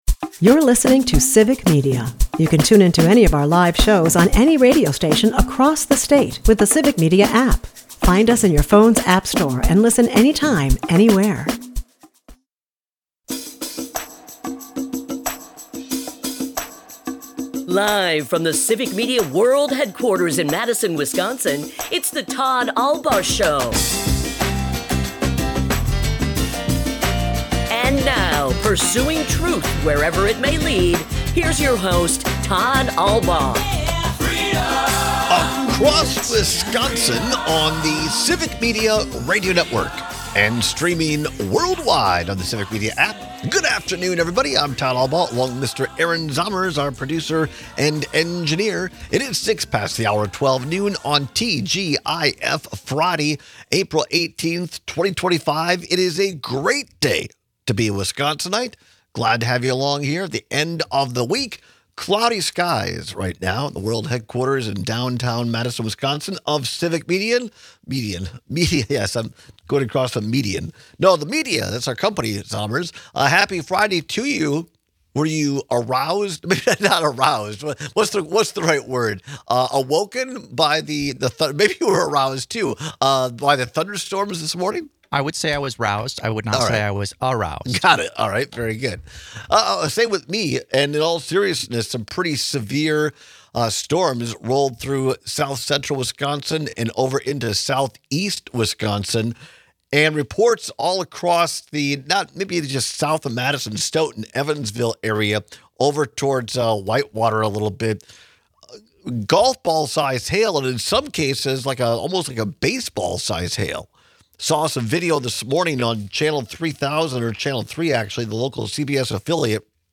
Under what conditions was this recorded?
We take some calls and texts with your thoughts. In other news, a Wisconsin Elections Committee meeting devolved into a shouting match yesterday evening.